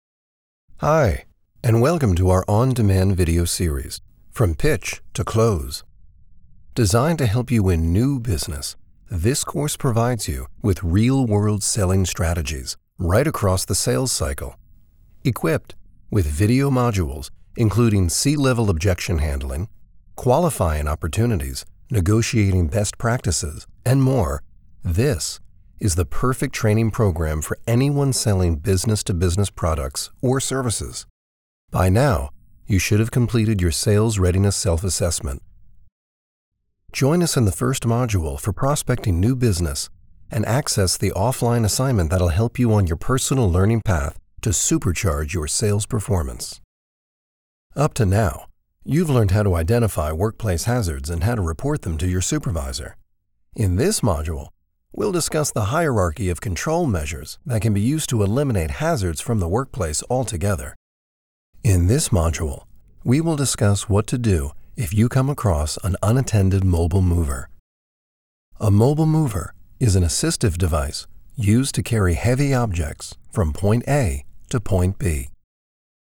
Male
Hi, thanks for stopping by! My voice is best characterized as warm, deep, and sincere.
E-Learning
E-Learning Demo (3 Spots)
0717FULL_NORM_ELEARN_DEMO.mp3